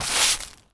Broom Sweeping
sweeping_broom_leaves_stones_15.wav